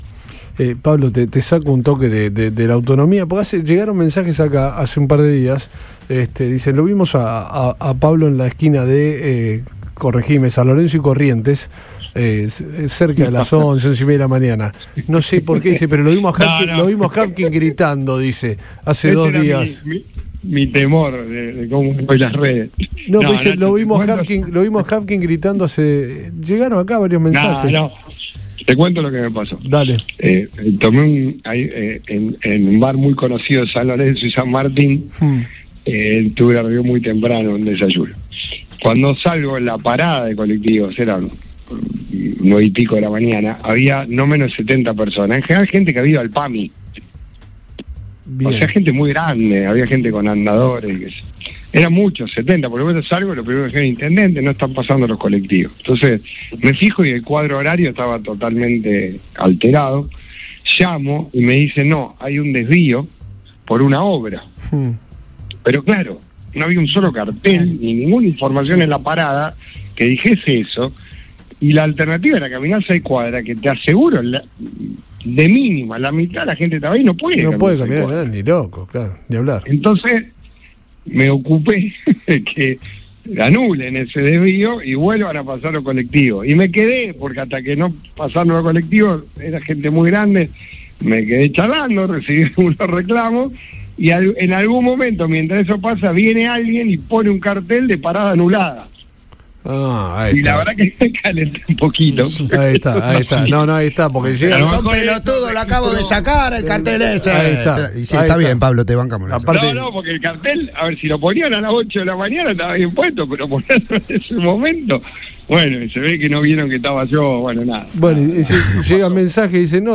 EN RADIO BOING
Pablo Javkin dialogó en Todo Pasa de Radio Boing y respondió a los mensajes que circularon en redes sociales y que lo señalaban “gritando” en la esquina de San Lorenzo y Corrientes.
ESCUCHÁ LA ANÉCDOTA DEL INTENDENTE